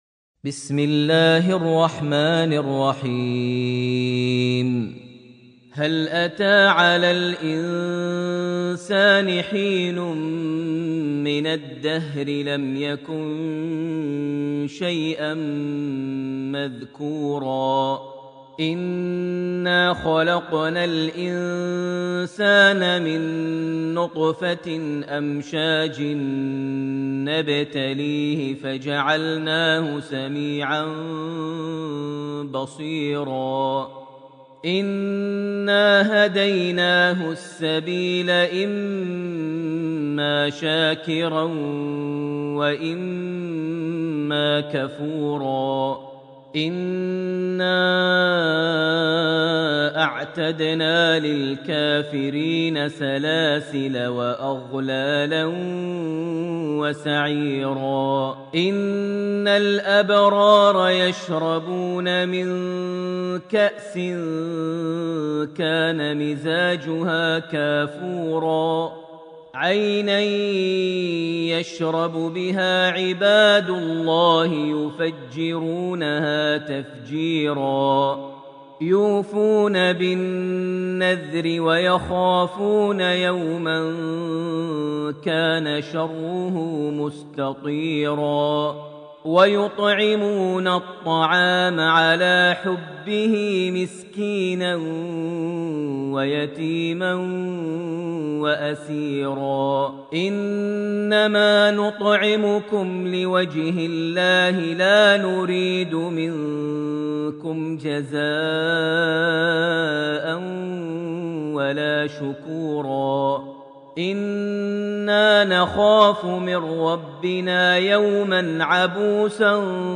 surat Al-ensan > Almushaf > Mushaf - Maher Almuaiqly Recitations